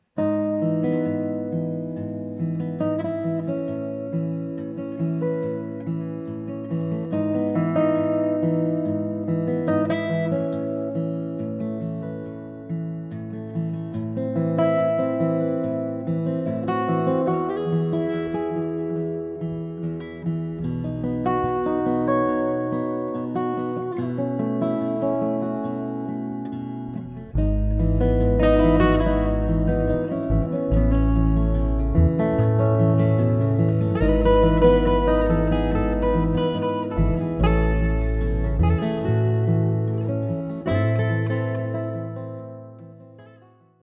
Martin HD 28 on this one.